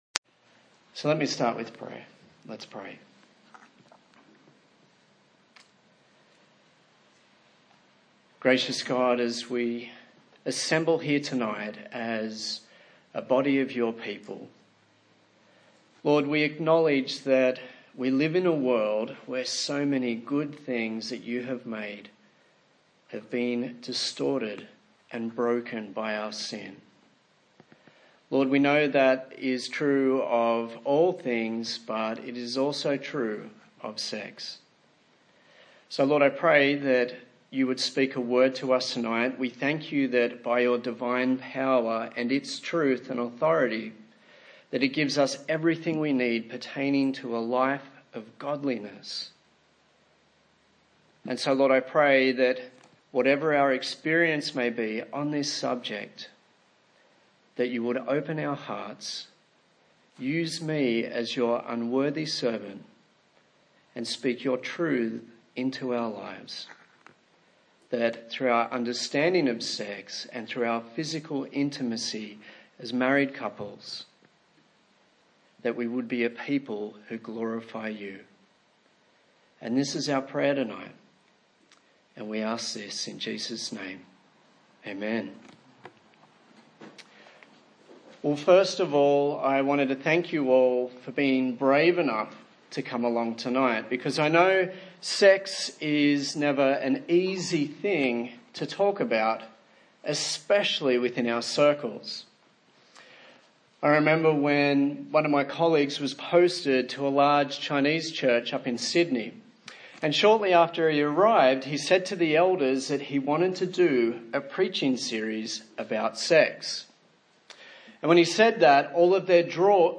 Service Type: TPC@5